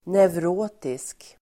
Ladda ner uttalet
Folkets service: neurotisk neurotisk adjektiv, neurotic Uttal: [nevr'å:tisk] Böjningar: neurotiskt, neurotiska Definition: som avser neuros; som lider av neuros neurotic adjektiv, neurotisk [psykologiskt]